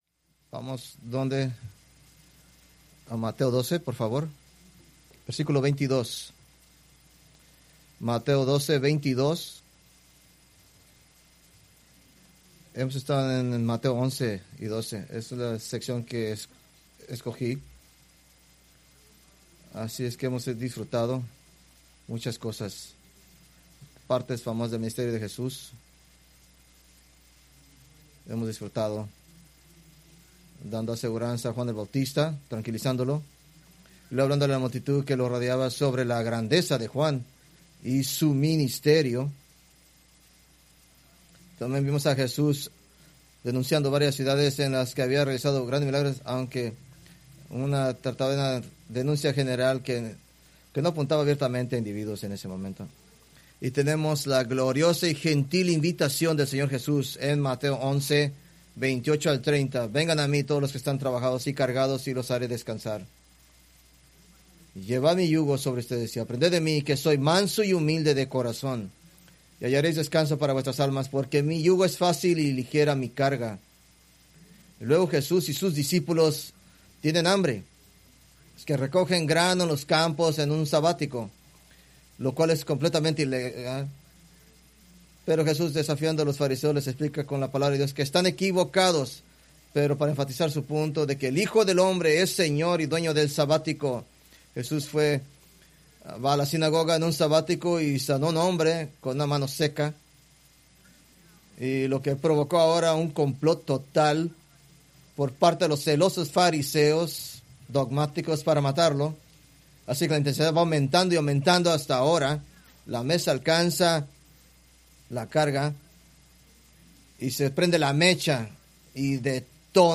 Preached November 16, 2025 from Mateo 12:22-32